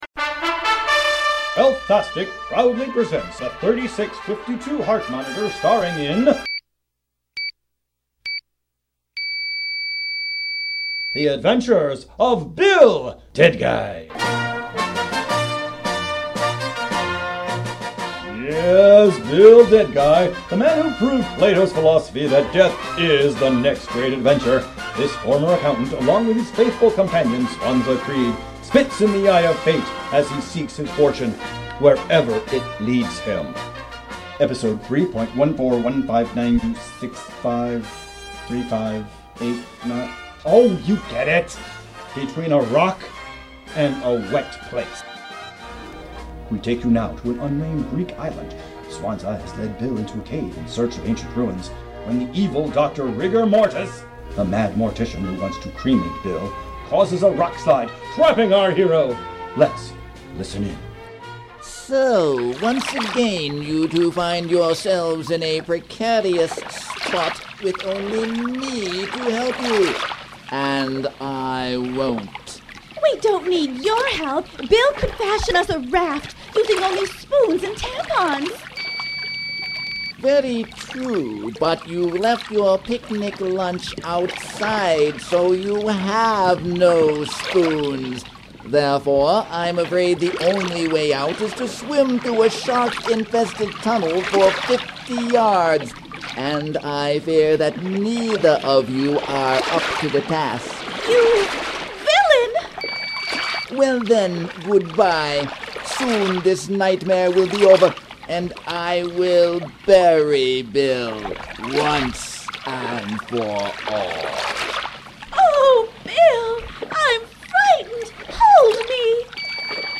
If that’s so Bill is the adventurer. Bill is played by a heart monitor.